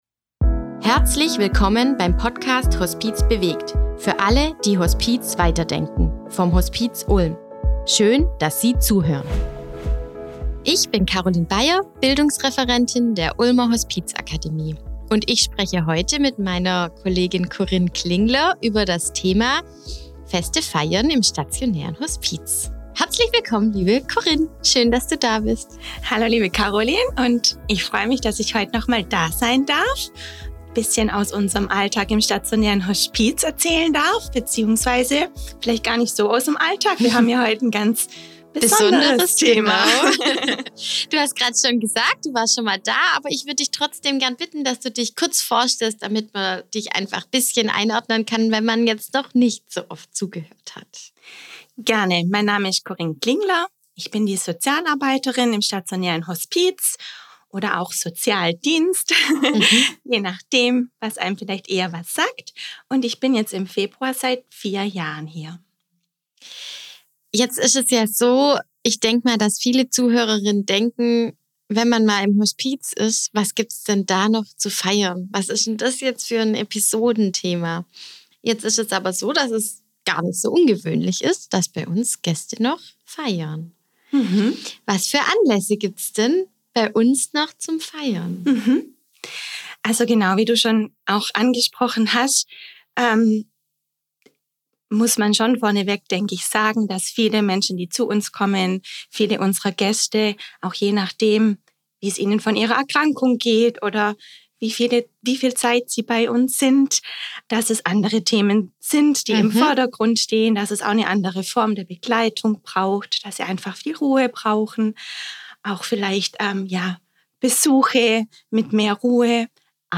im Gespräch mit Sozialarbeiterin